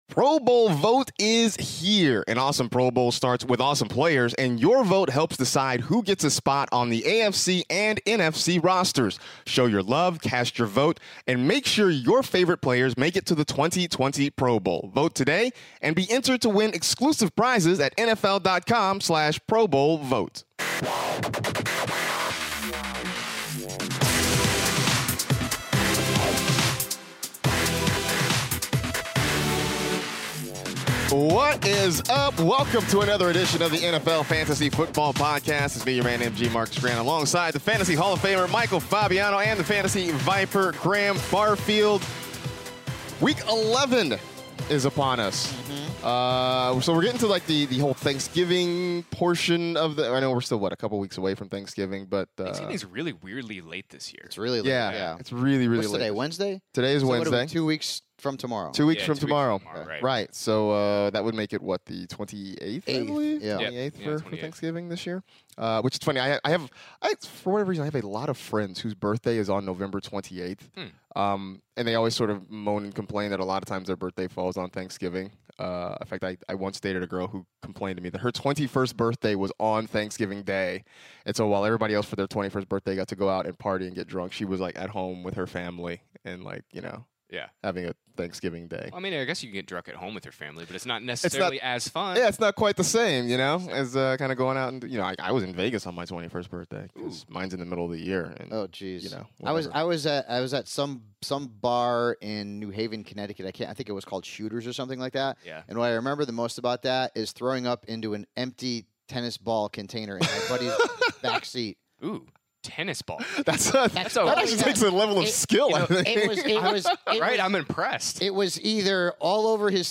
are back in the studio to help you with your fantasy team heading to the Week 11 games!